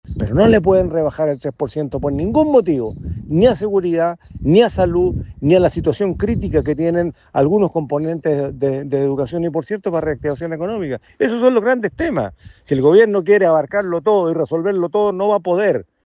El senador de la DC, Iván Flores, cuestionó eventuales recortes en áreas como Educación y Salud, considerando -según planteó- las propias prioridades definidas por el Gobierno.